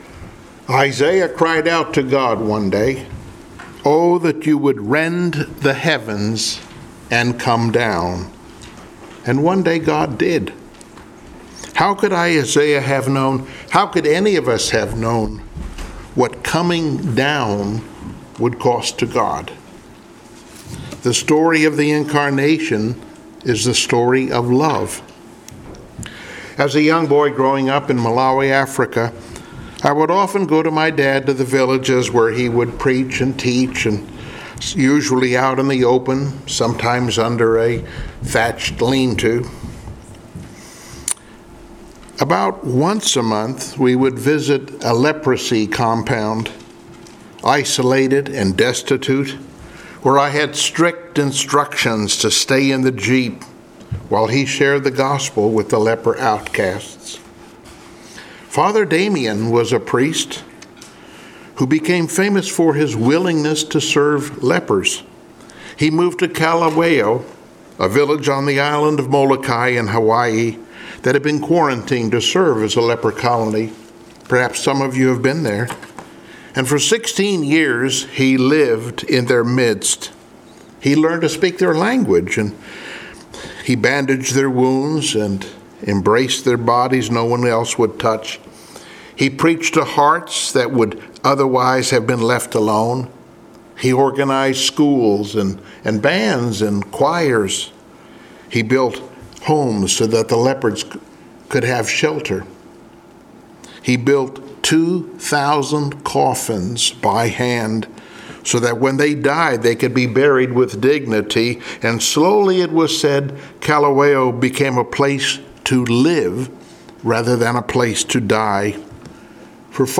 Passage: II Kings 5:1-19 Service Type: Sunday Morning Worship Download Files Notes Bulletin Topics